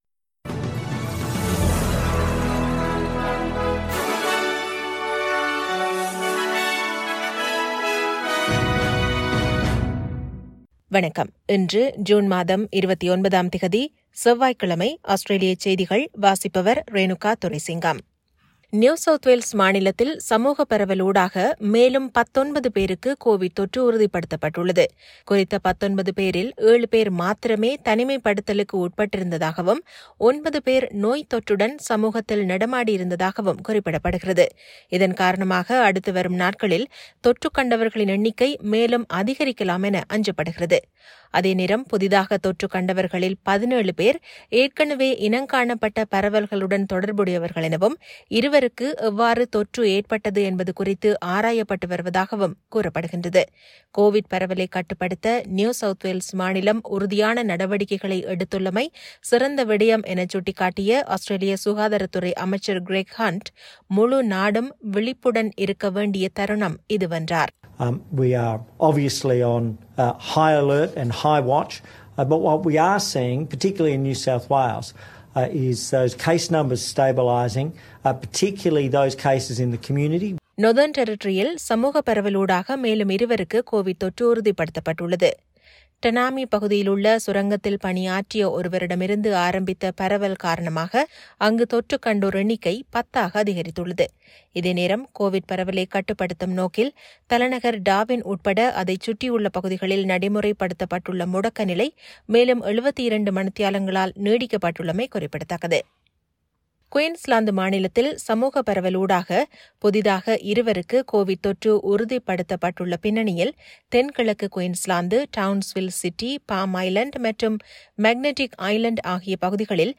SBS தமிழ் ஒலிபரப்பின் இன்றைய (செவ்வாய்க்கிழமை 29/06/2021) ஆஸ்திரேலியா குறித்த செய்திகள்.